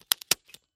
Звуки степлера
Офисный степлер еще один вариант